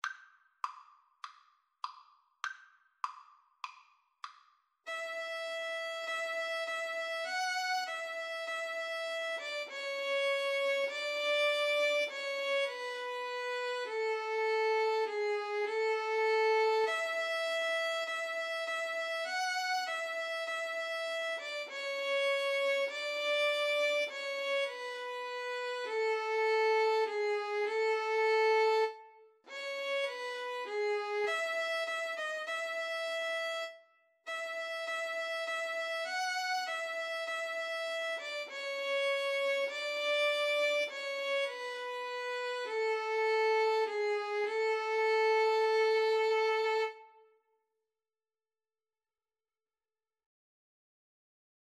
Moderato
Traditional (View more Traditional Violin-Viola Duet Music)